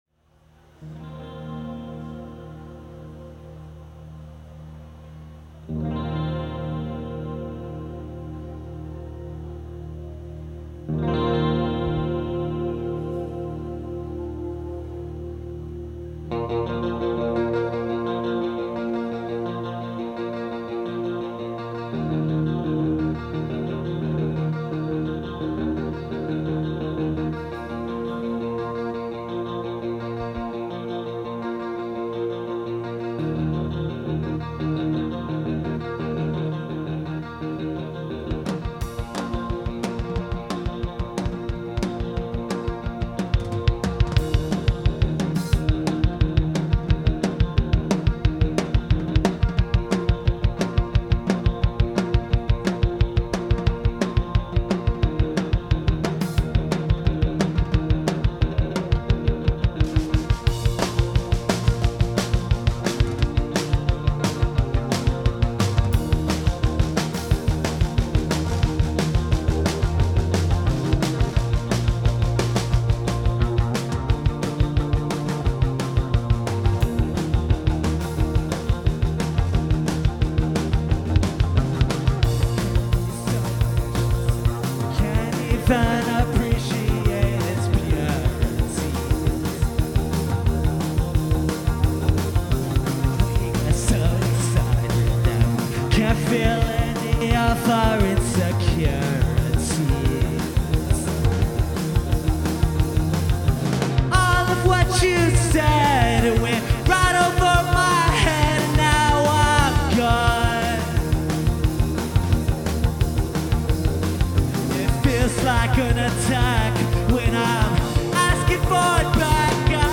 What Indie from Brooklyn is sounding like these days.